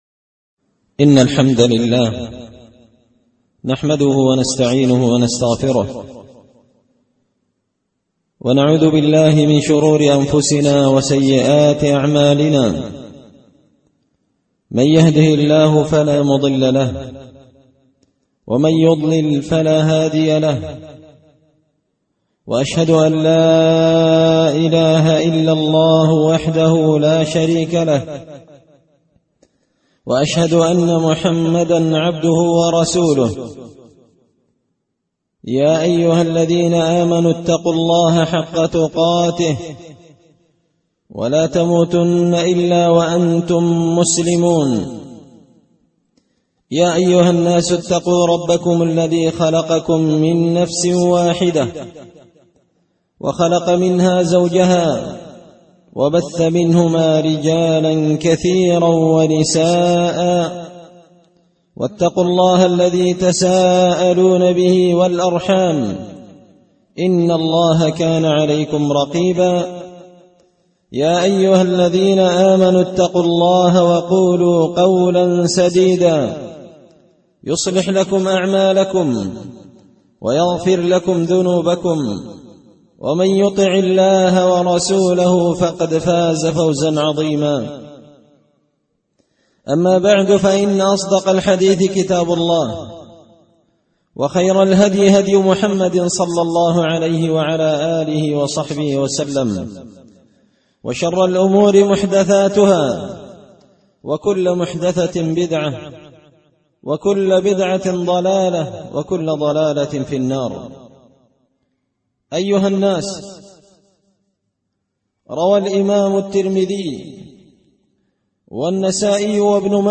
خطبة جمعة بعنوان – الكبروالغلول والدّين
دار الحديث بمسجد الفرقان ـ قشن ـ المهرة ـ اليمن